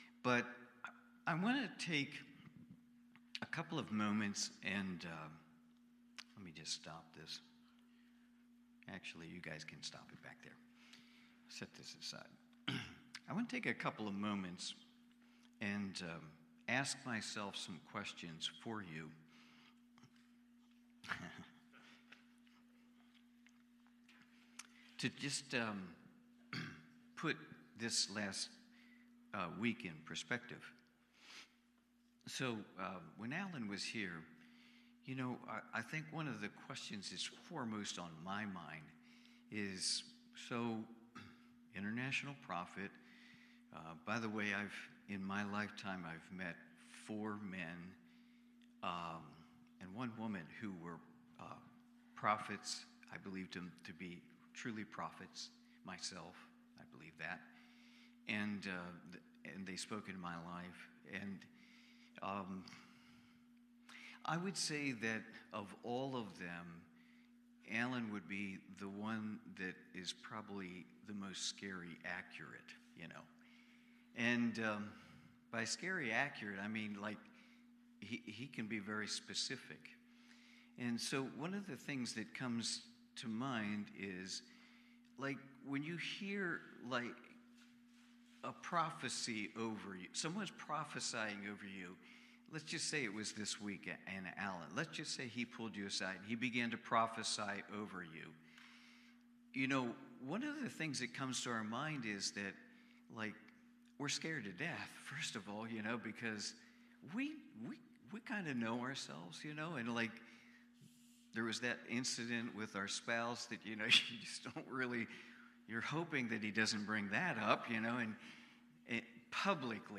Prayer Watch Listen Save Cornerstone Fellowship Sunday morning service, livestreamed from Wormleysburg, PA.